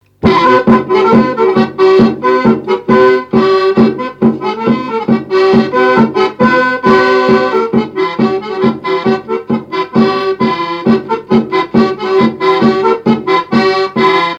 Couplets à danser
branle : courante, maraîchine
répertoire à l'accordéon chromatique et grosse caisse
Pièce musicale inédite